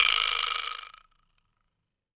wood_vibraslap_hit_05.wav